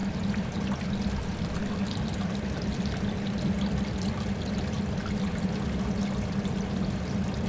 DRAINPIPE -S.WAV